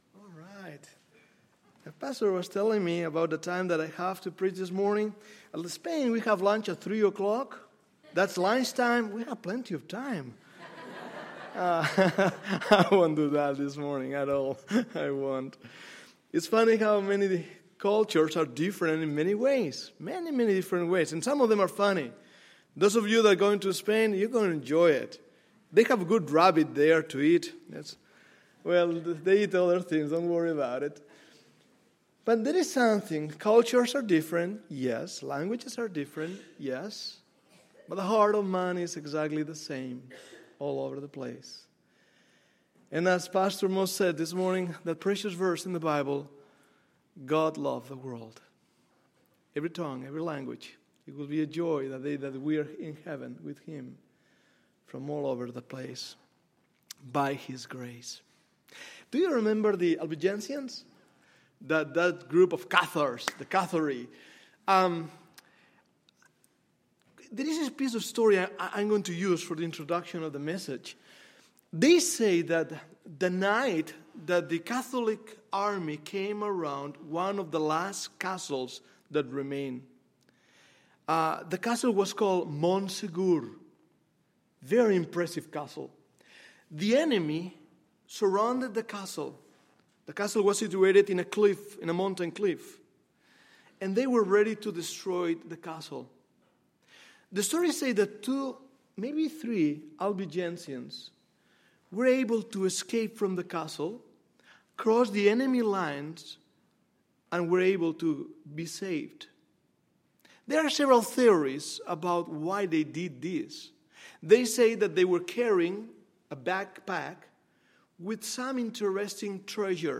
Sunday, September 23, 2012 – Sunday AM Session